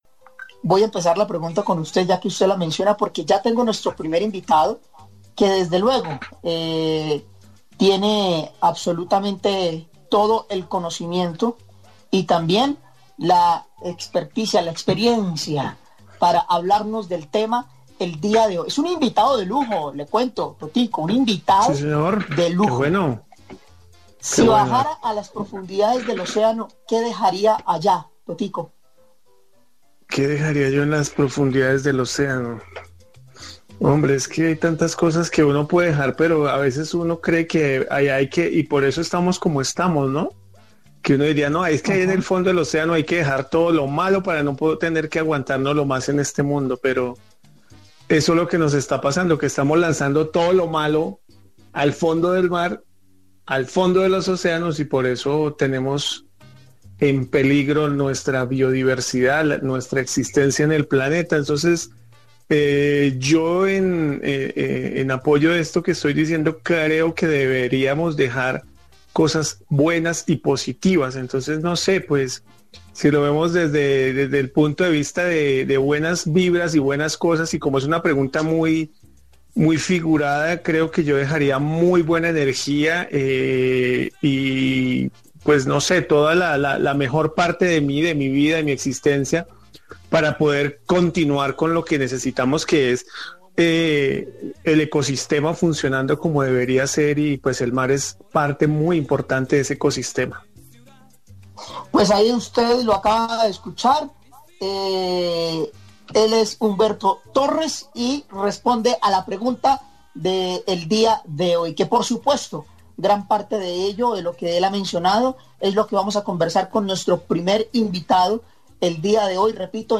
en entrevista con Colmundo Radio